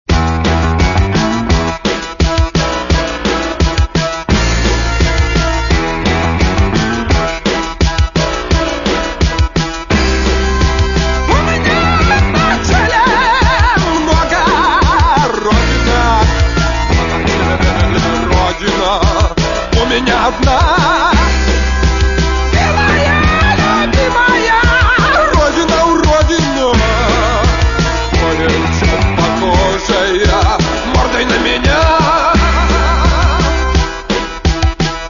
Рок та альтернатива